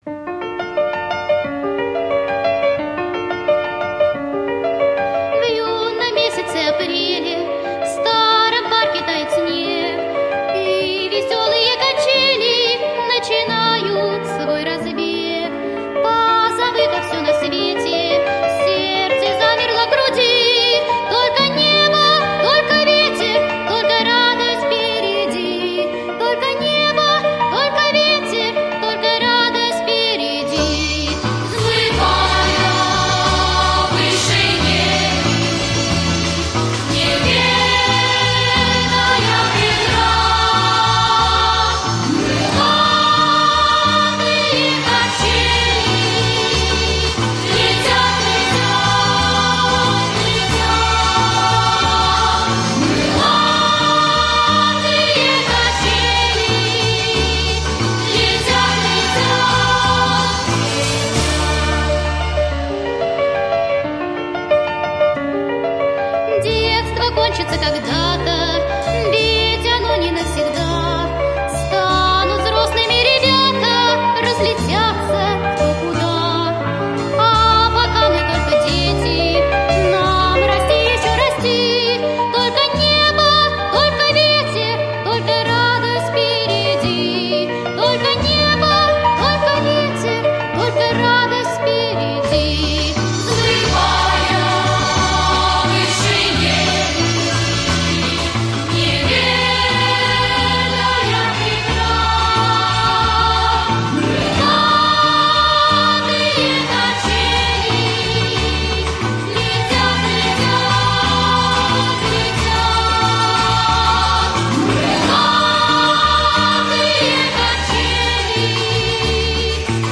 песня из советского мультфильма